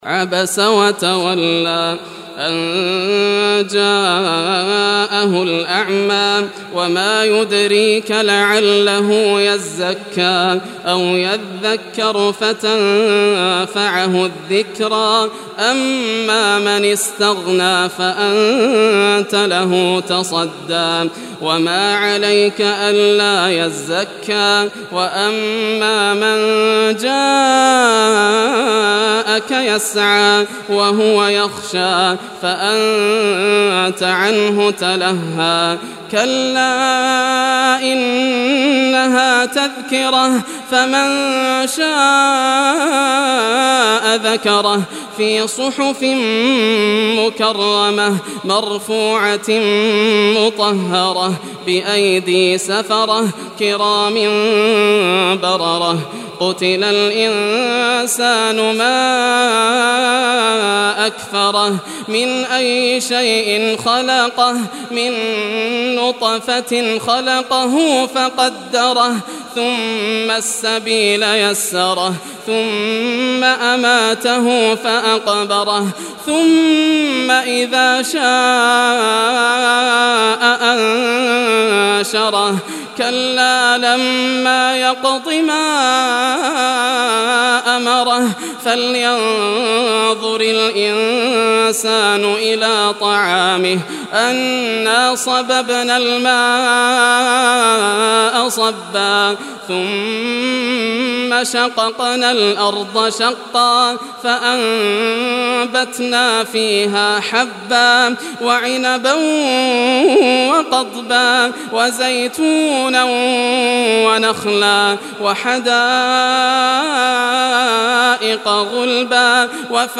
Surah Abasa Recitation by Yasser al Dosari
Surah Abasa, listen or play online mp3 tilawat / recitation in Arabic in the beautiful voice of Sheikh Yasser al Dosari.
80-surah-abasa.mp3